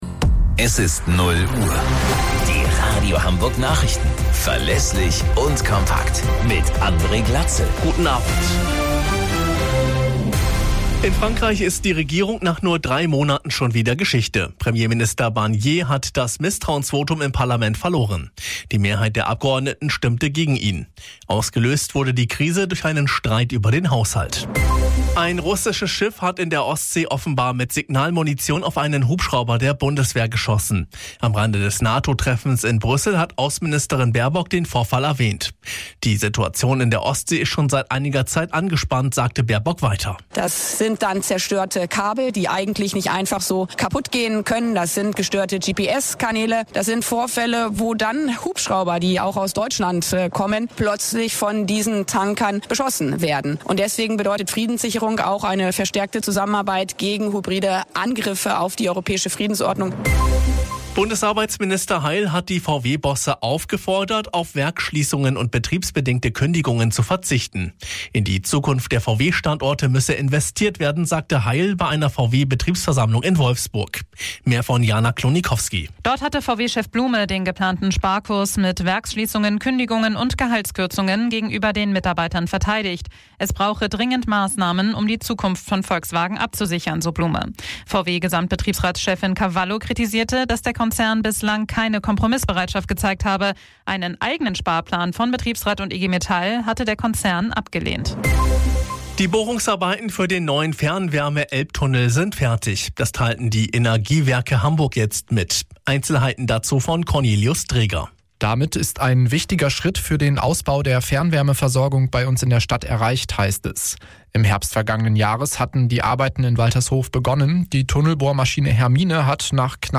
Radio Hamburg Nachrichten vom 05.12.2024 um 06 Uhr - 05.12.2024